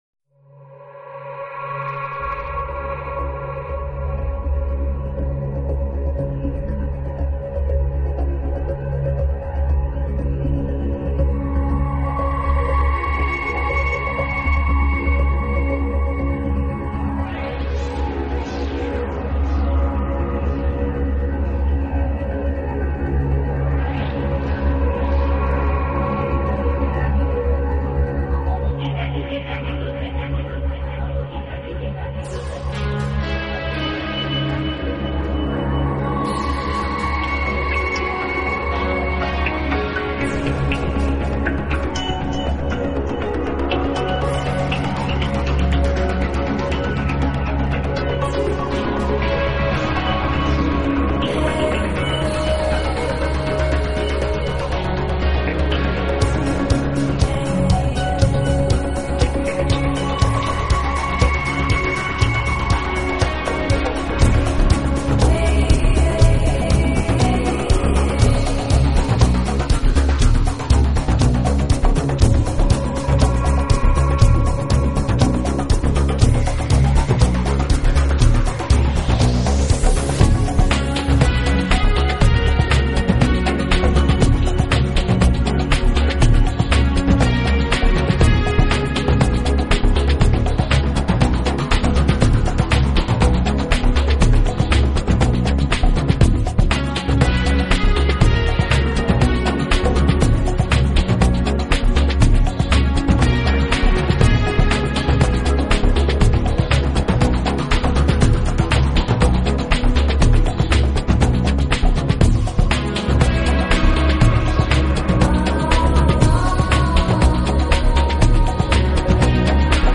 一路伴随着女声激情澎湃的梦幻唱吟， 让我们心灵也不由得为之
乐曲飘渺绵长，时而大气十足，时而委婉缠绵， 为不可多得的新世纪音